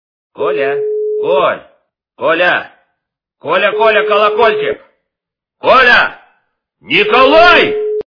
» Звуки » Именные звонки » Именной звонок для Николая - Коля, Коль, Коля, Коля калакольчик, Коля, Никлай
При прослушивании Именной звонок для Николая - Коля, Коль, Коля, Коля калакольчик, Коля, Никлай качество понижено и присутствуют гудки.